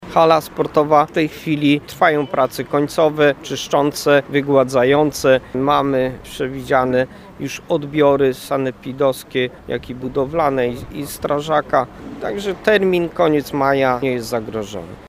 Mówi burmistrz Niska Waldemar Ślusarczyk: